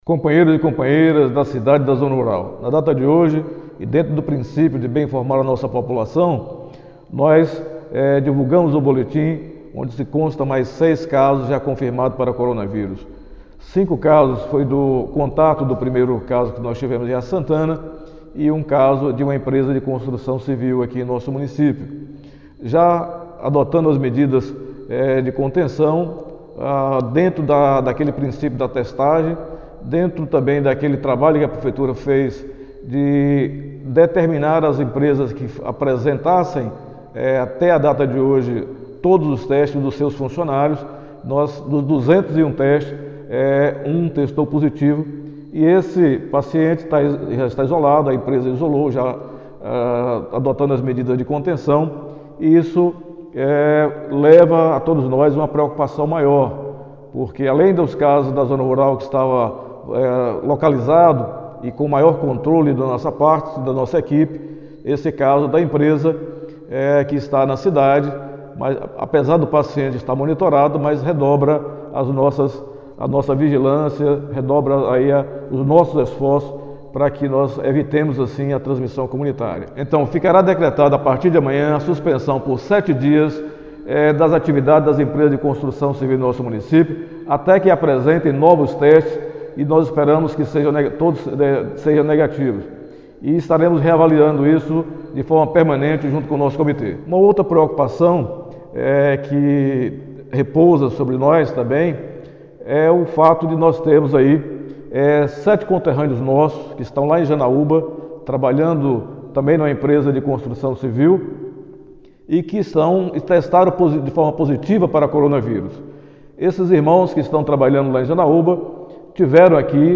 Após a divulgação do novo boletim, o prefeito de Riacho de Santana Alan Vieira comentou sobre a situação do município e pediu mais uma vez para que a população contribua no combate ao vírus.
Prefeito-Alan-sobre-10-caso.mp3